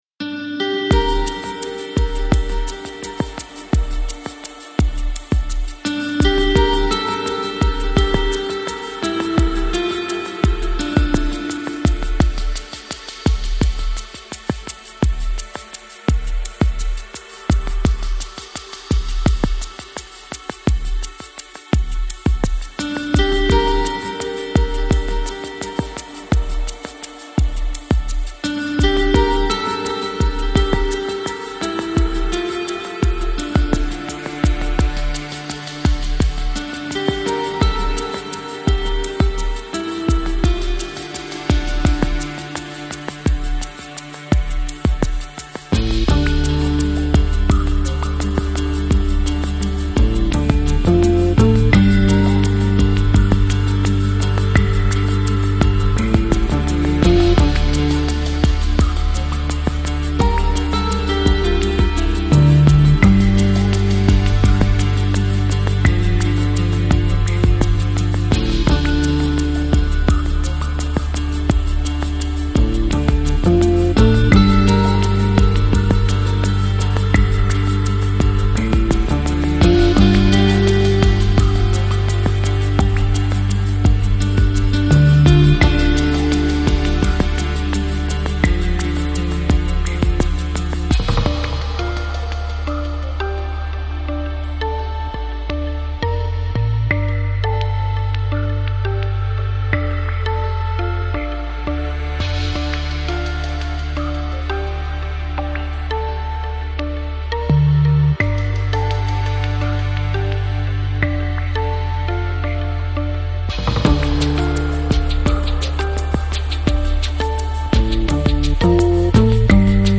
Lo-Fi, Lounge, Chillout Год издания